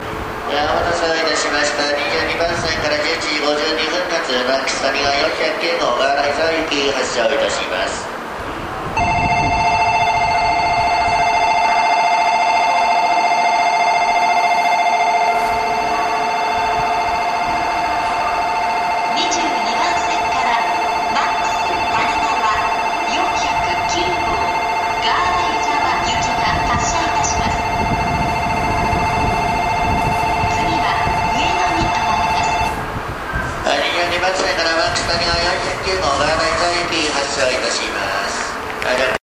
発車メロディーベルです。時間帯関係なくこのくらいは長くなりますね。
新幹線ホームは２面４線となっておりスピーカーはNational天井型となっています。